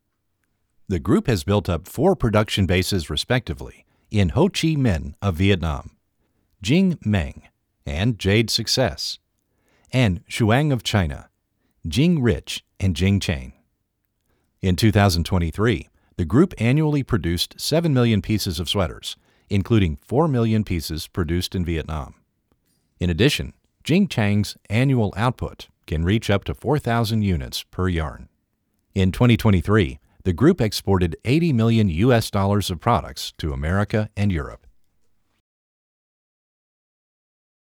w144-低沉成熟样音
w144-低沉成熟样音.mp3